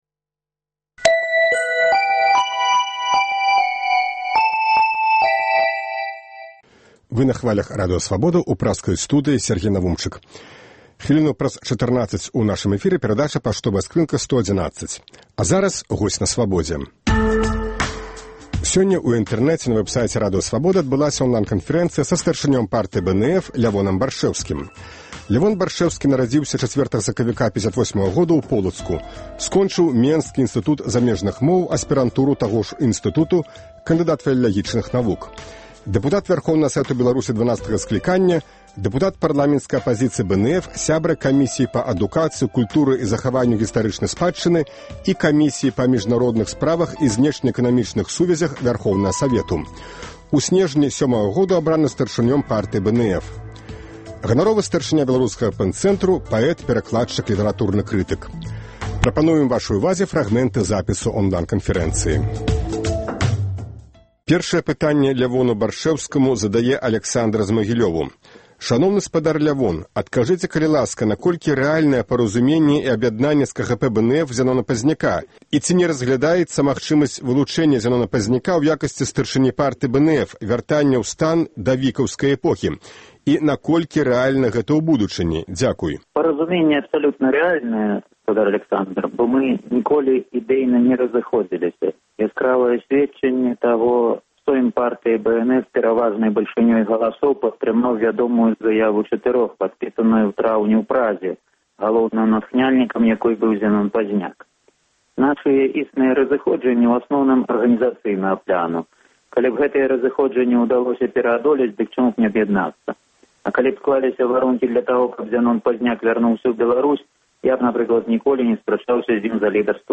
Запіс онлай-канфэрэнцыі са старшынём Партыі БНФ Лявонам Баршчэўскім